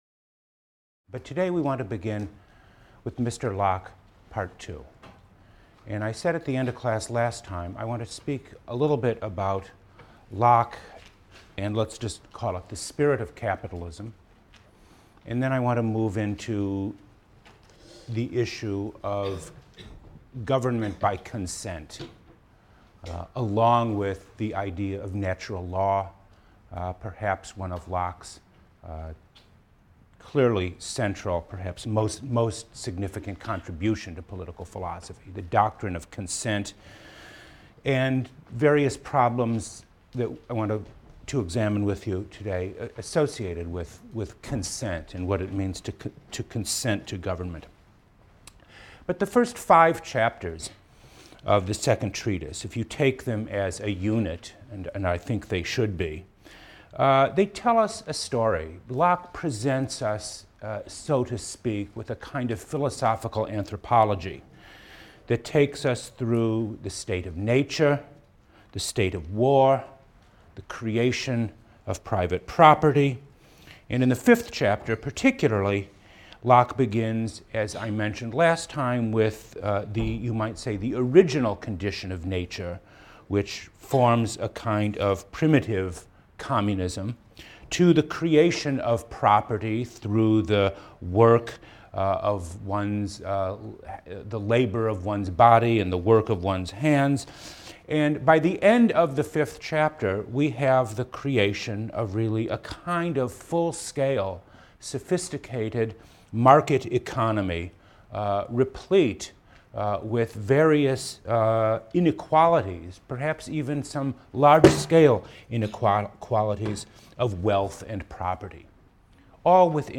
PLSC 114 - Lecture 16 - Constitutional Government: Locke, Second Treatise (7-12) | Open Yale Courses